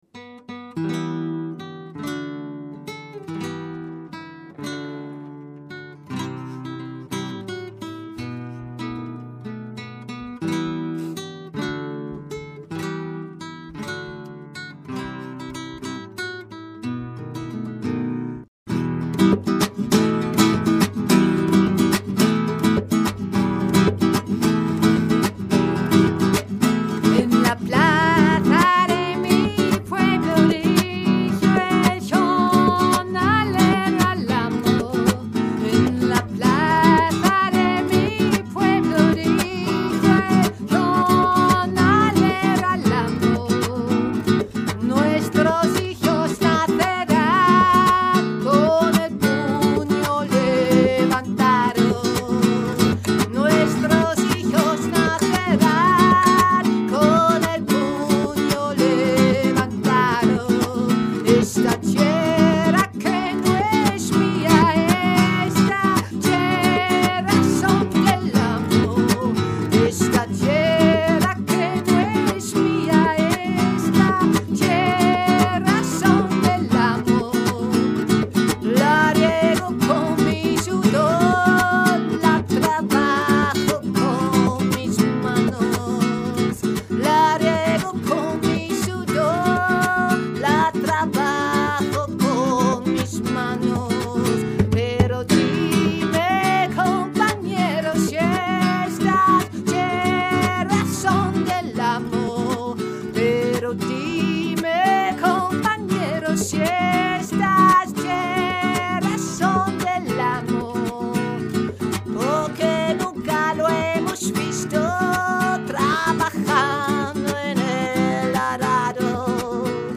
Gedichte und Lieder aus dem Spanischen Bürgerkrieg (1936–1939)
Mit Flamencogitarre, Gesang und Hörinstallation zelebrieren wir eine Klangcollage, die an ihre beispiellose Zivilcourage und internationale Solidarität, an ihre Schicksale, ihre Texte und Lieder erinnert.